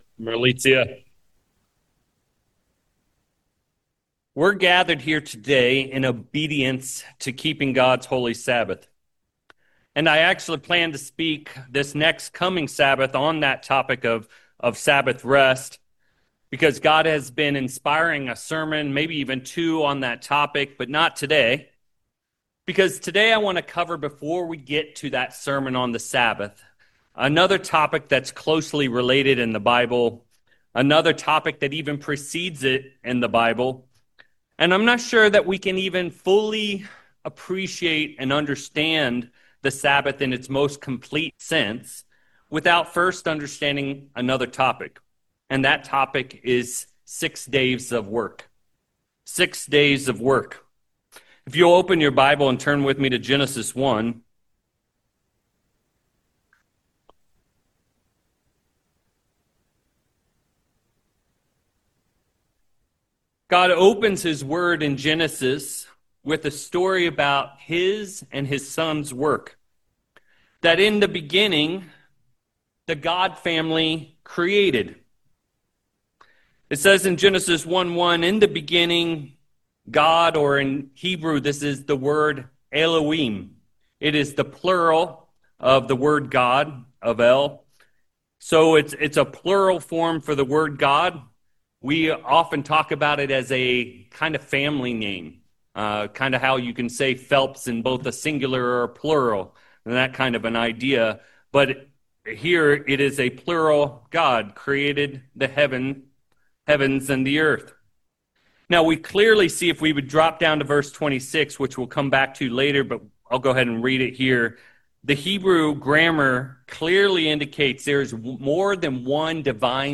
Given in San Francisco Bay Area, CA San Jose, CA Petaluma, CA